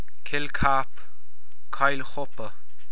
Audio File (.wav) Pronunciation Audio File